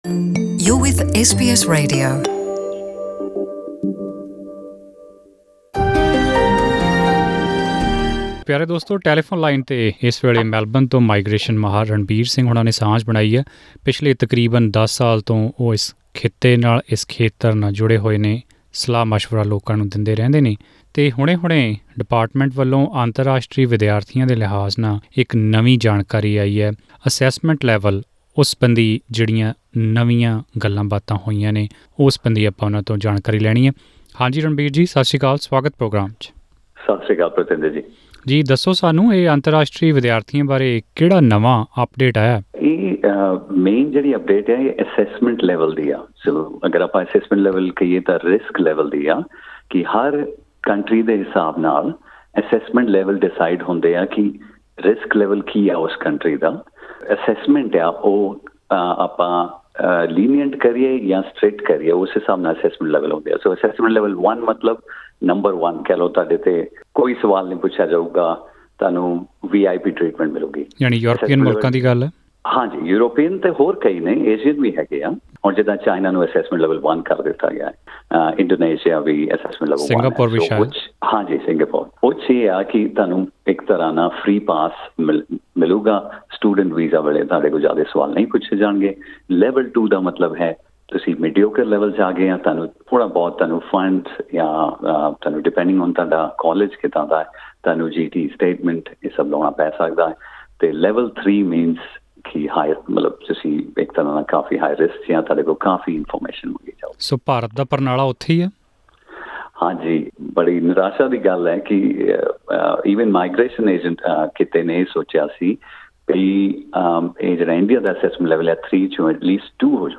In an interview with SBS Punjabi